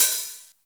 Index of /90_sSampleCDs/Club-50 - Foundations Roland/KIT_xTR909 Kits/KIT_xTR909 2
CYM XC.HAT08.wav